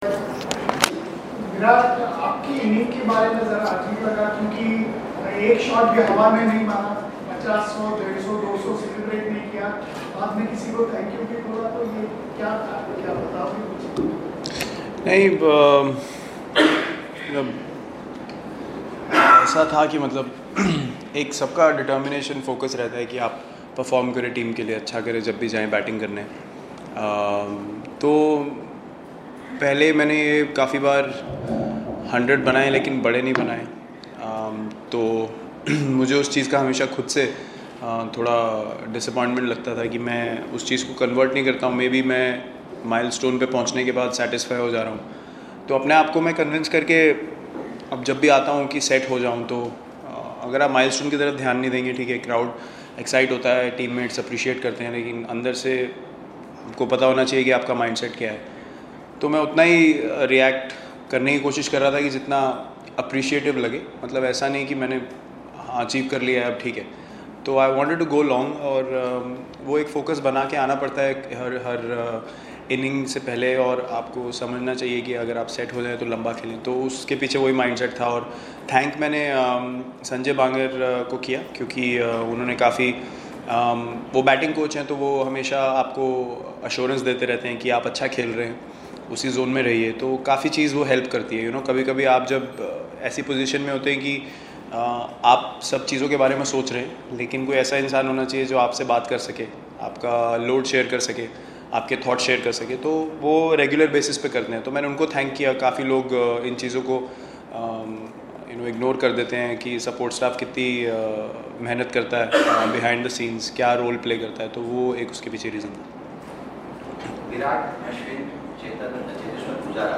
Virat Kohli' Post-match Conference in Indore